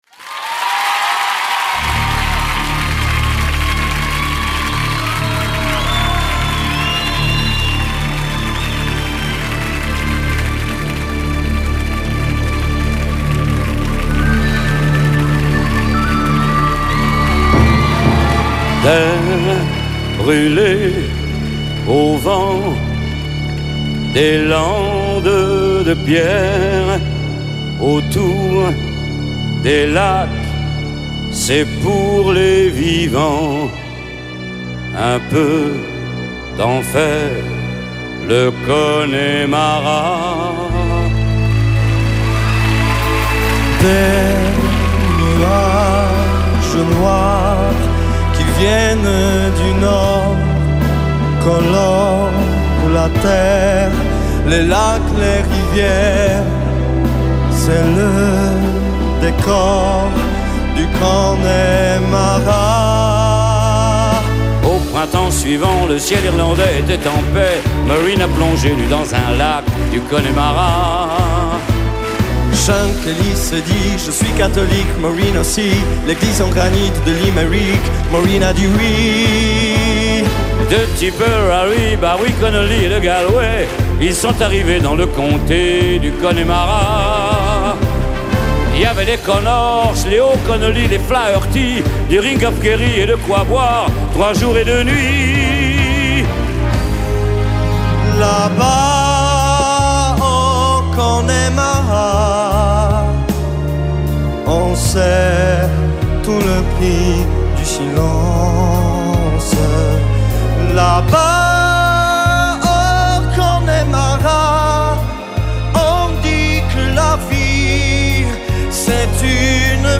DUOS